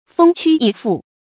蜂趨蟻附 注音： ㄈㄥ ㄑㄩ ㄧˇ ㄈㄨˋ 讀音讀法： 意思解釋： 比喻很多人迎合投靠。